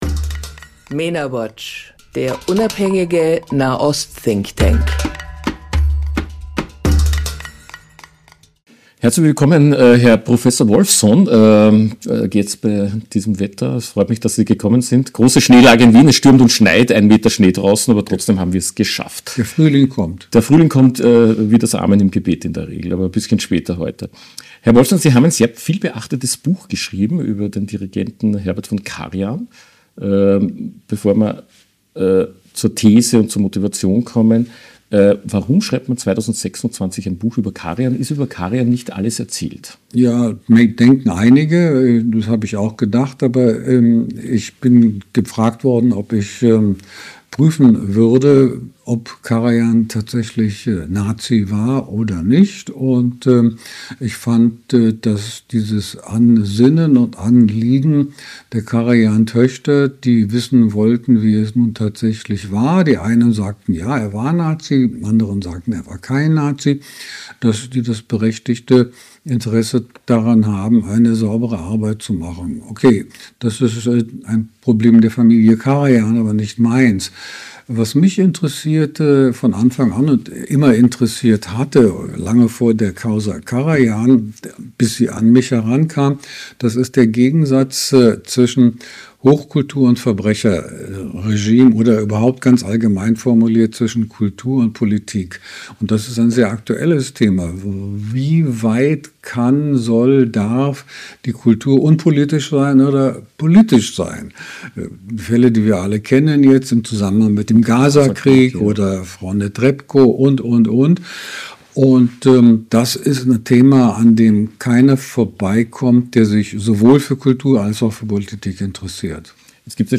Im Interview mit Mena Watch spricht der Historiker Michael Wolffsohn über sein neues Buch über Herbert von Karajan, den er vom Nazivorwurf entlastet.